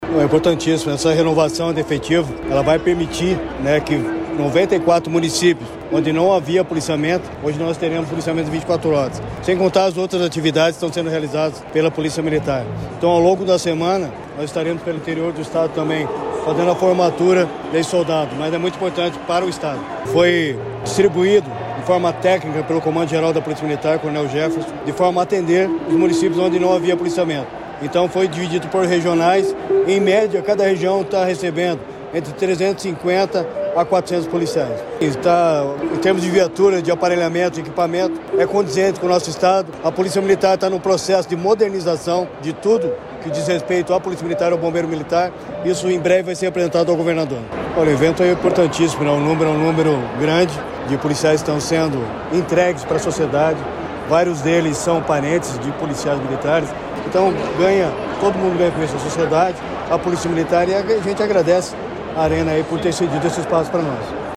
Sonora do secretário da Segurança Pública, Hudson Teixeira, sobre a formatura de 1.452 policiais militares para a macrorregião de Curitiba | Governo do Estado do Paraná